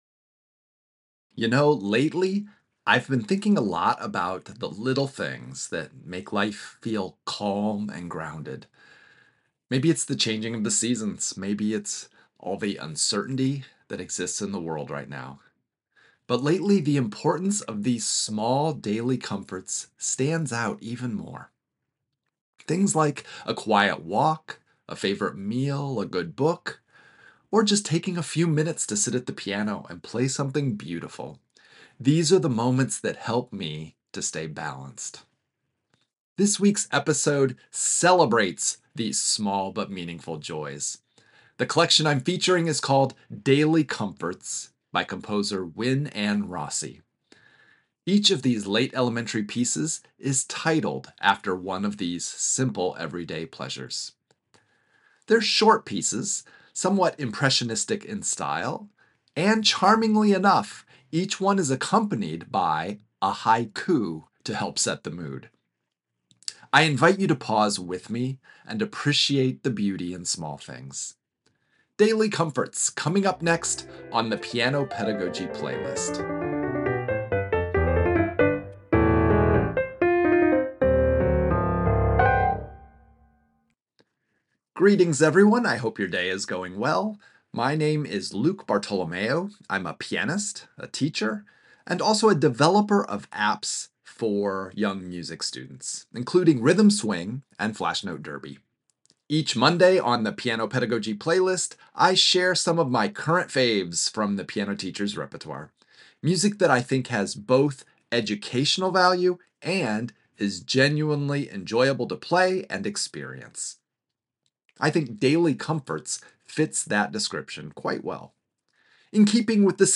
lyrical piano solos
Through impressionistic harmonies and gentle surprises